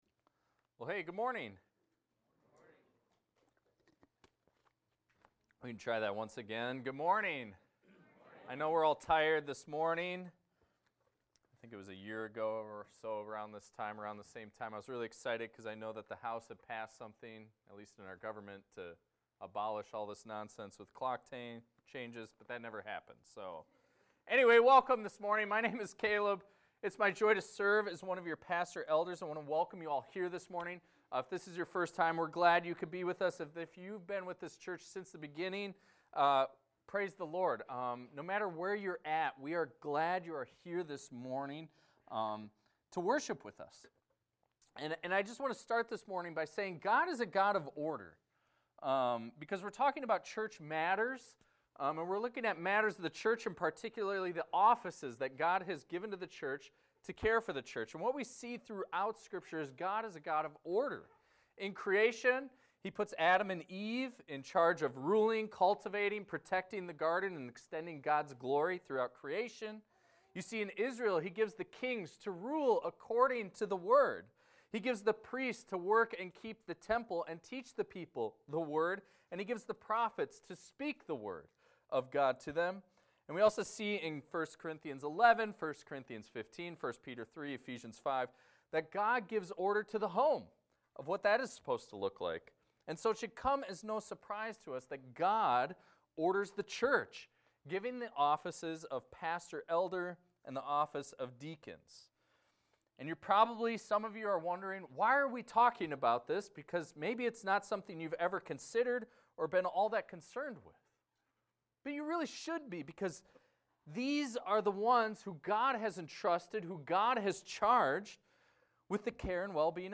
A sermon about deacons in the church titled "Deacons: Part 1"